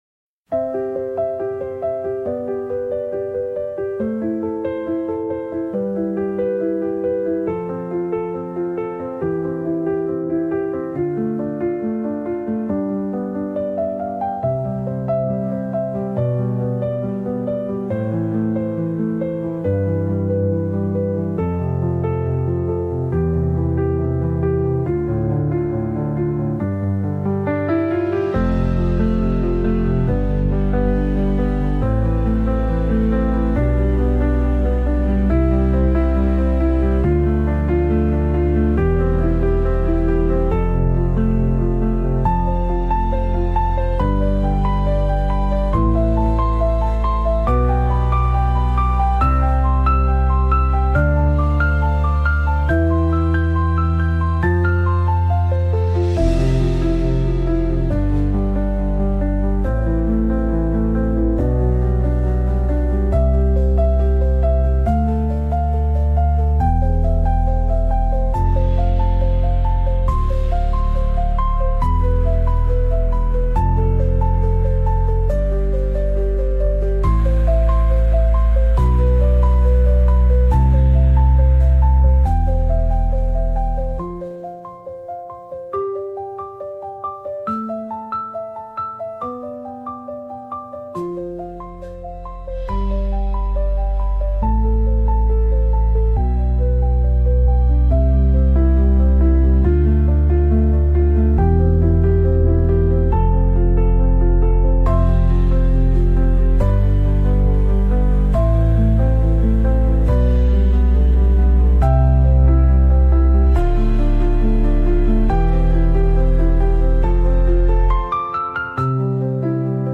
ListenWatch on YouTube  Ambient piano